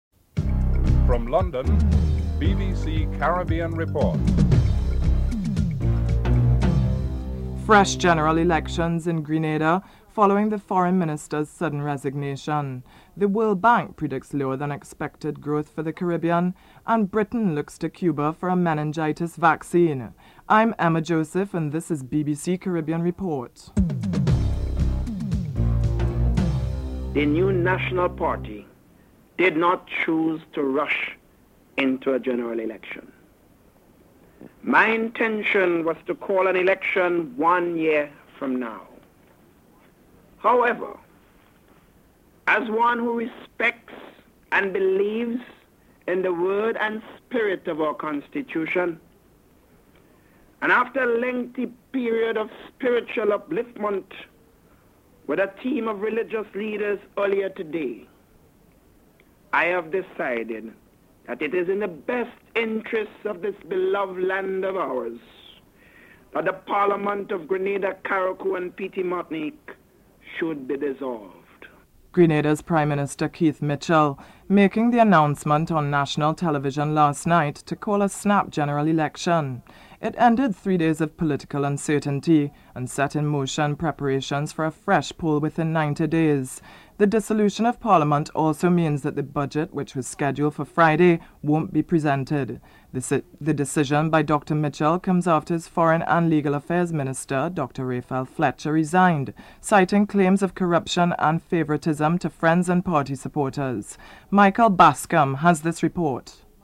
1. Headlines (00:00-00:25)
5. Britain looks to Cuba for a meningitis vaccine. Foreign Minister of State Tony Lloyd is interviewed (14:00-15:16)